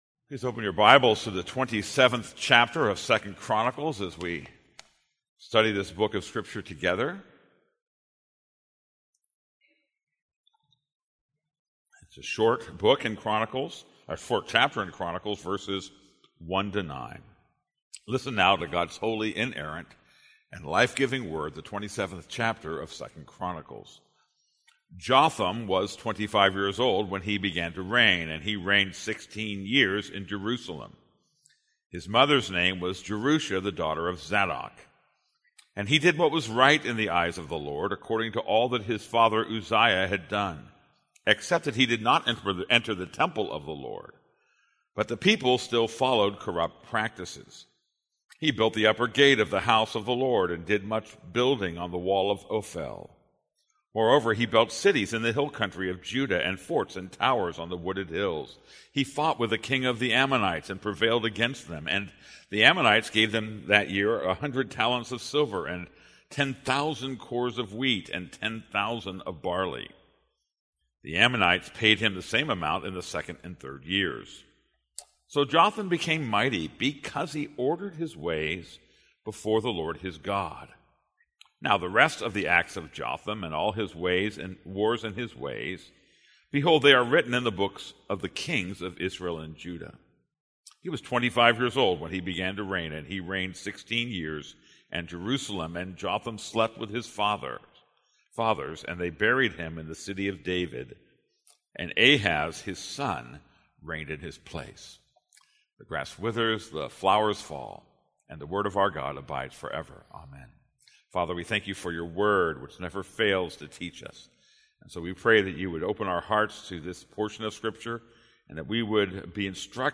This is a sermon on 2 Chronicles 27:1-9.